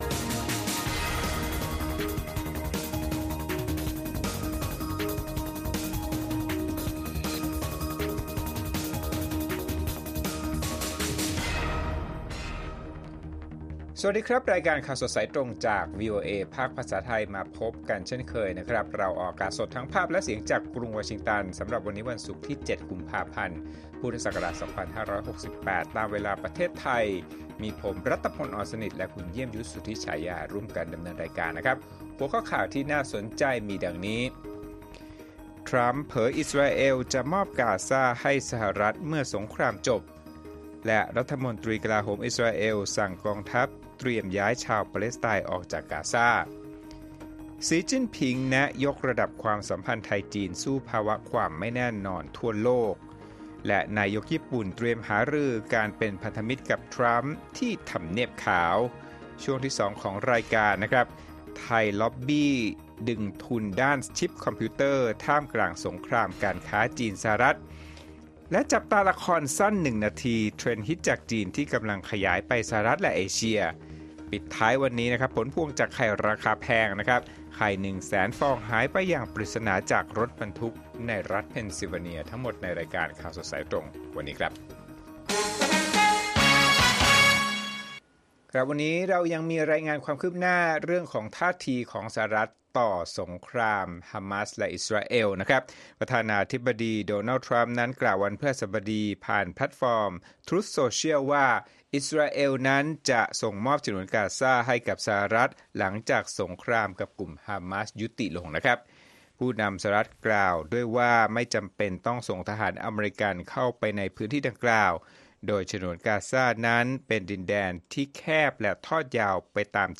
ข่าวสดสายตรงจากวีโอเอไทย วันศุกร์ ที่ 7 ก.พ. 2568